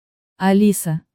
Звуки женских имён
Звук робота, произносящего женское имя Алиса